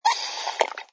sfx_slurp_glass08.wav